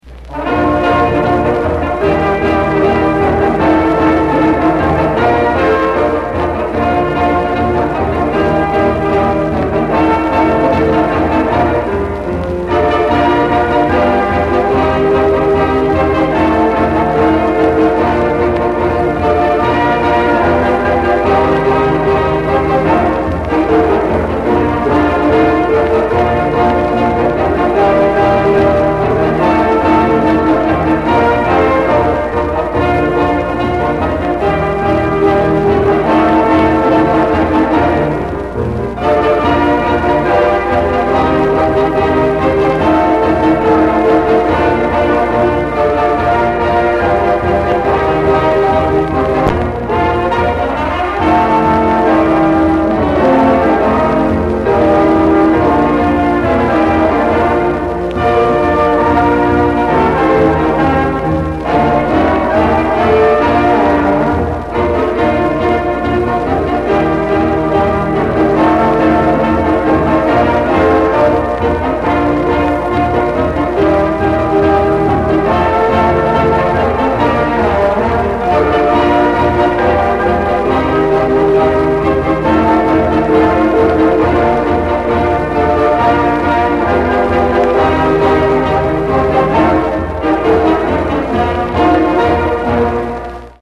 High School Dixieland Band at White Sulphur Springs, Montana
Piano
Trumpet
Clarinet
Sax
Trombone
Drums
Audio is compromised by acoustic echo and record scratches